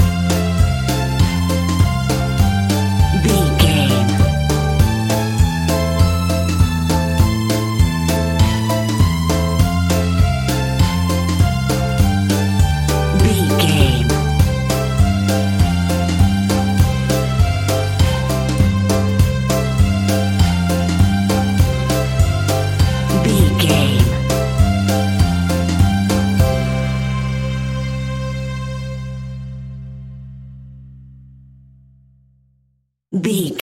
Ionian/Major
kids instrumentals
childlike
cute
happy
kids piano